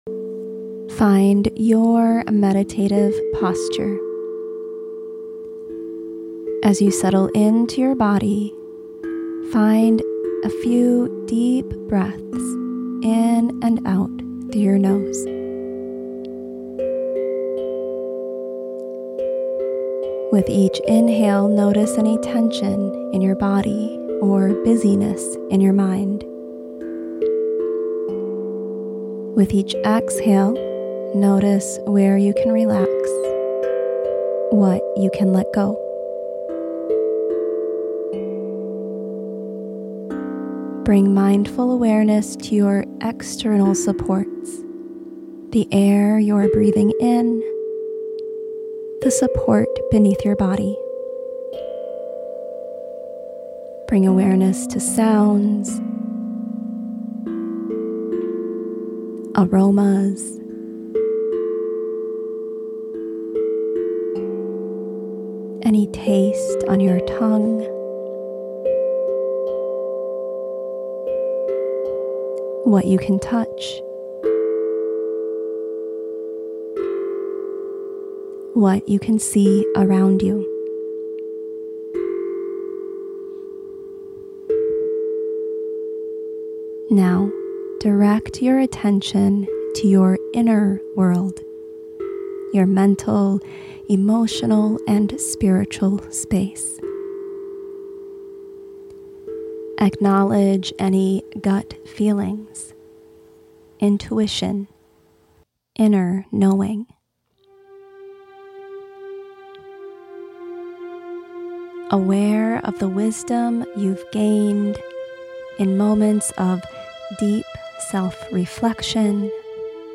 Did you enjoy this guided meditation?